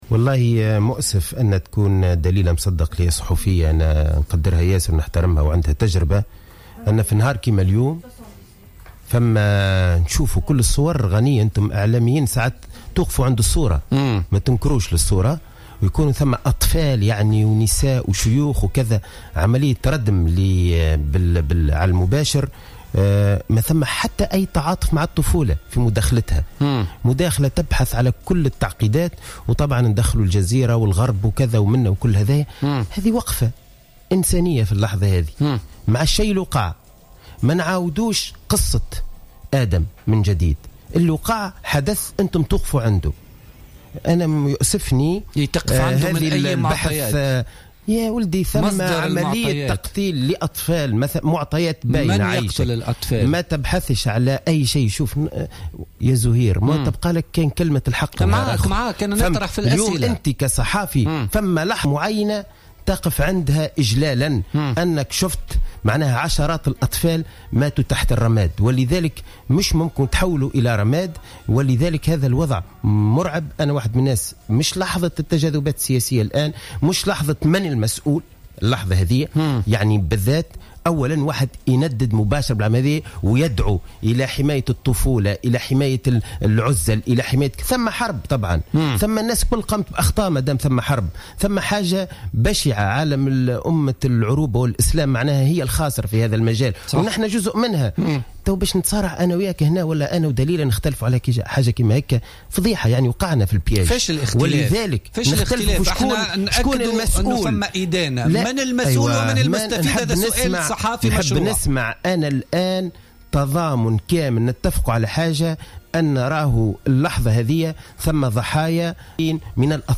مشادة كلامية بين حسين الجزيري وصحفي سوري